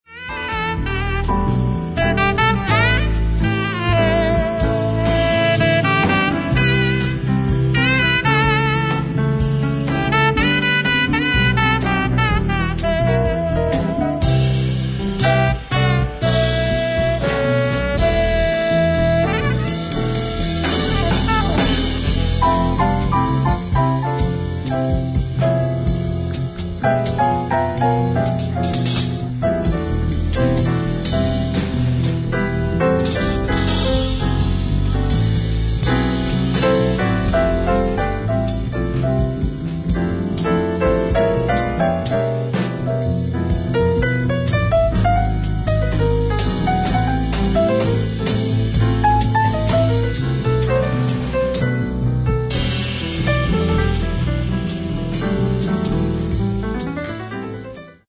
ライブ・アット・ニース・ジャズフェスティバル、ニース、フランス 07/17/1988
※試聴用に実際より音質を落としています。